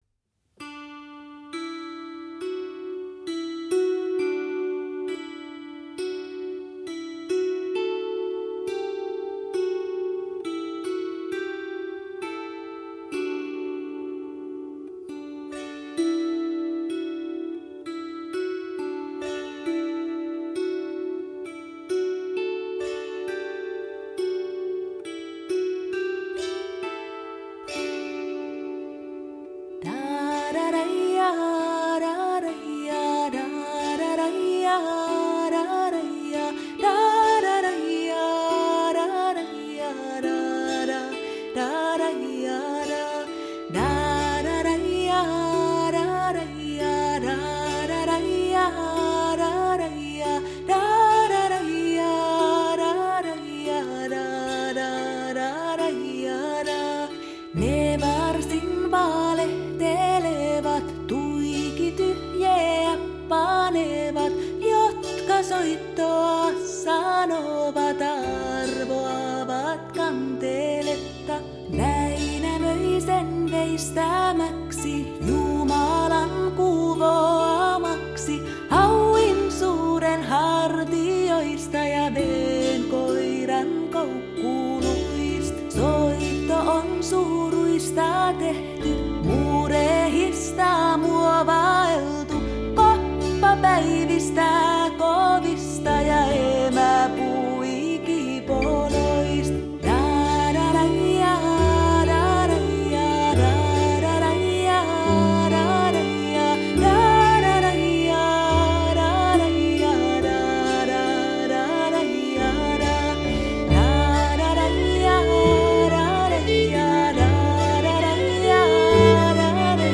super relaxanteee
Musica relaxante... sha